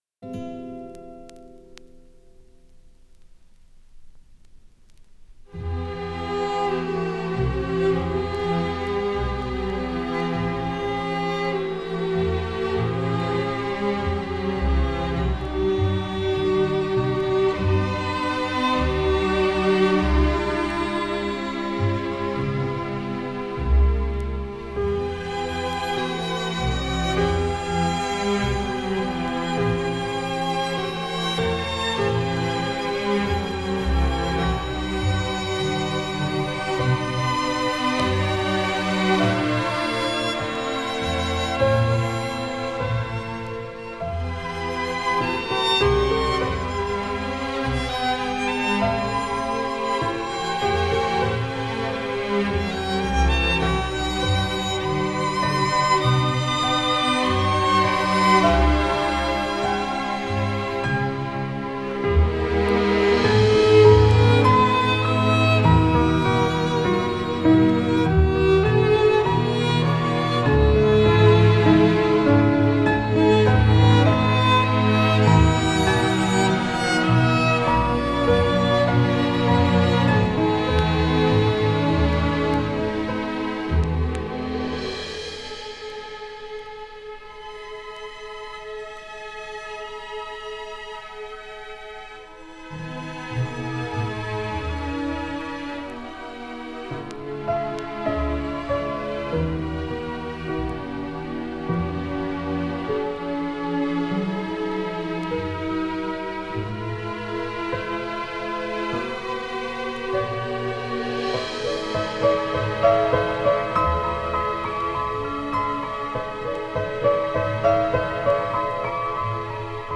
Ze zijn trouwens niet mono, maar stereo.
Daar zit zoveel rumble in.